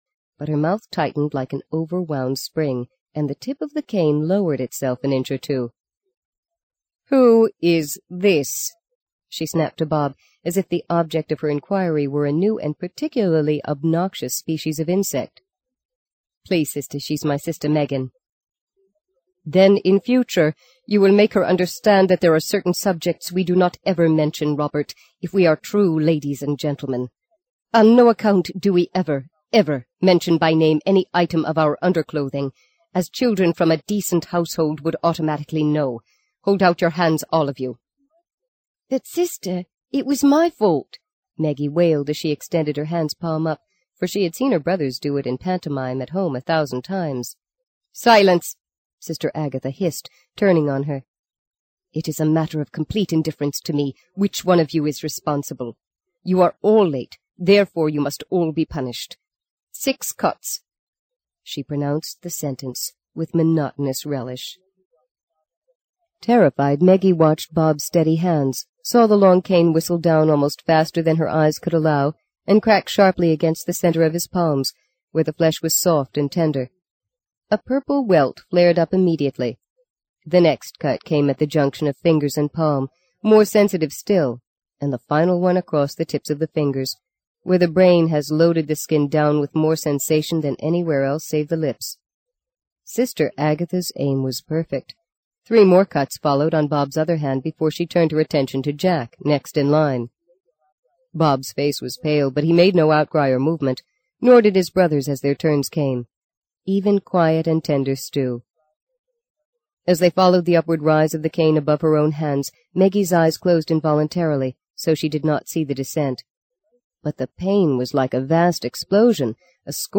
在线英语听力室【荆棘鸟】第二章 07的听力文件下载,荆棘鸟—双语有声读物—听力教程—英语听力—在线英语听力室